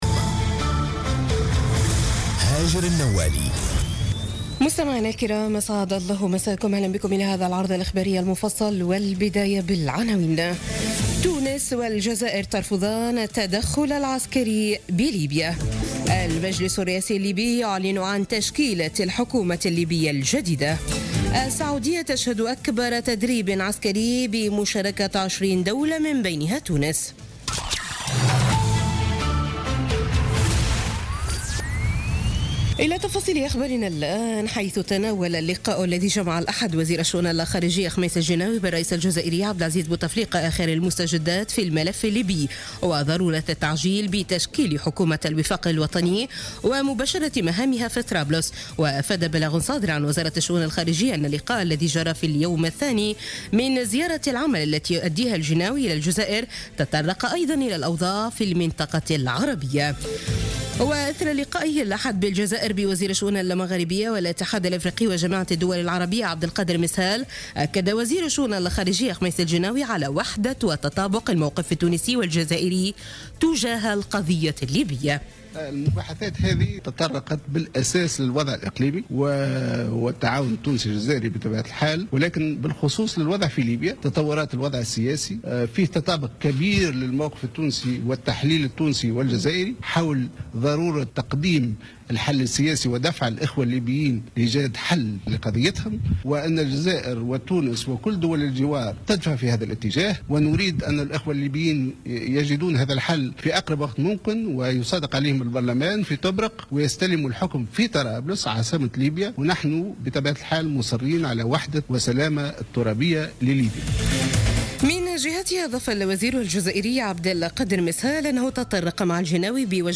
Journal Info 00h00 du Lundi 14 février 2016